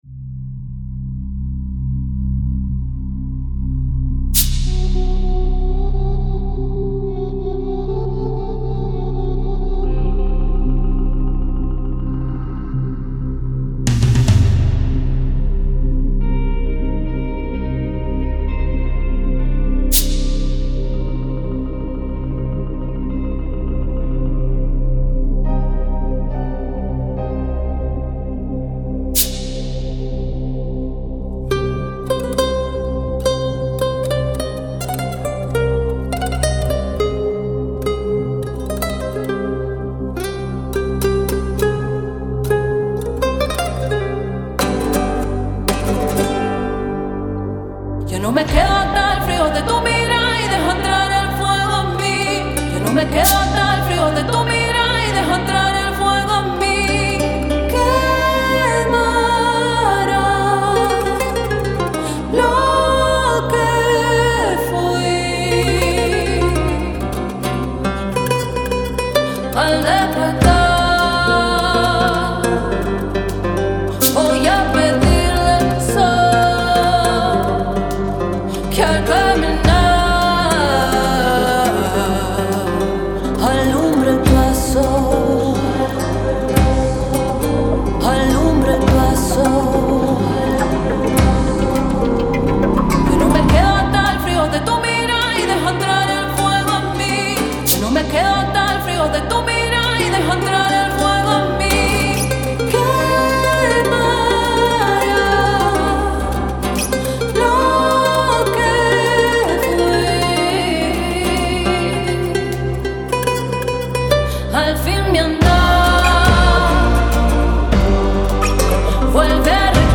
Vocal performance
Guitar